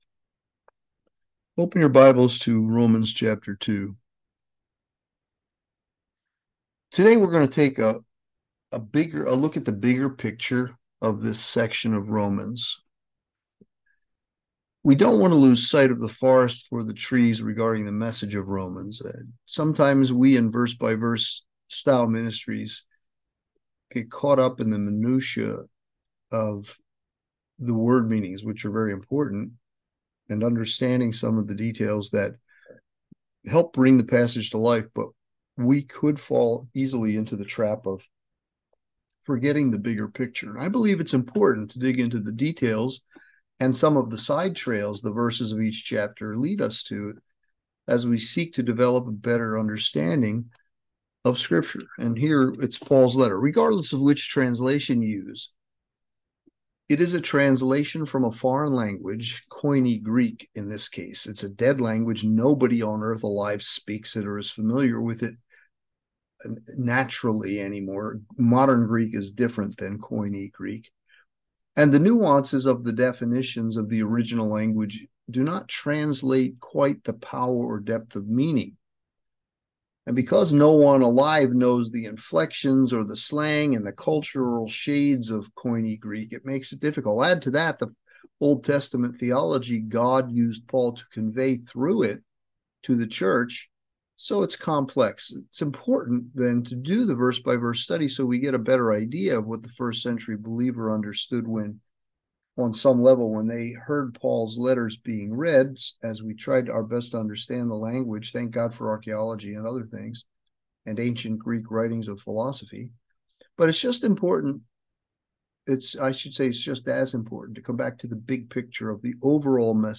Sermons | Calvary Chapel on the King's Highway